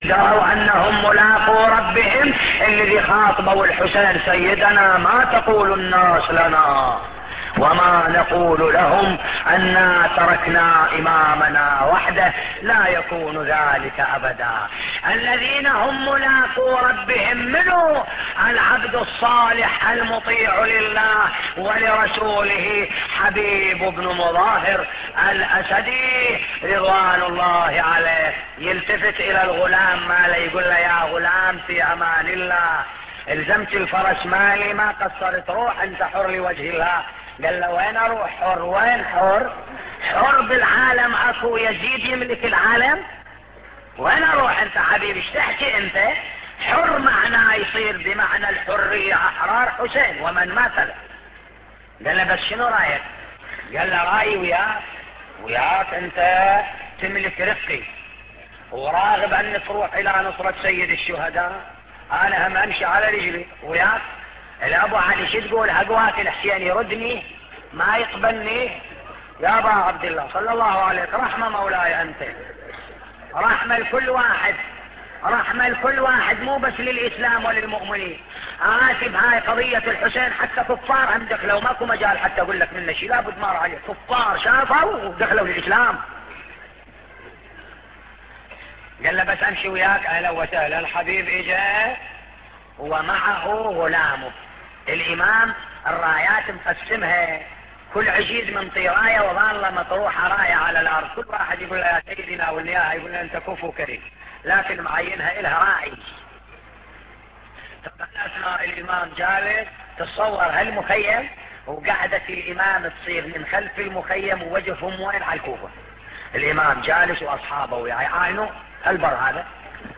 نواعي حسينية 4